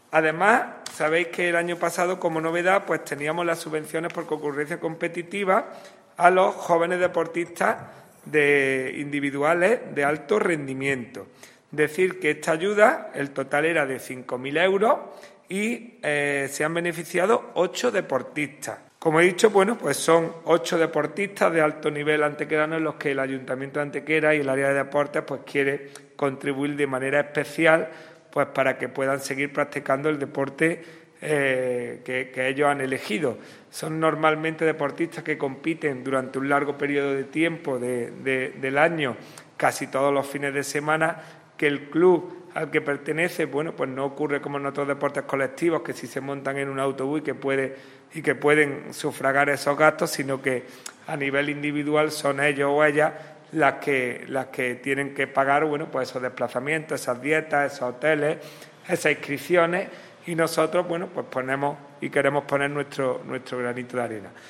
El teniente de alcalde delegado de Deportes, Juan Rosas, ha informado en rueda de prensa sobre las subvenciones municipales por concurrencia competitiva que ha otorgado el Ayuntamiento de Antequera en el año 2021 tanto a clubes deportivos como a deportistas jóvenes de alto nivel. 60.000 euros ha sido el total de las ayudas ya resueltas –el 80 por ciento de ellas ya están incluso ingresadas– que se han aportado desde el Consistorio para ayudar a competir tanto a 27 clubes (3 más que en 2020) y 8 deportistas individuales.
Cortes de voz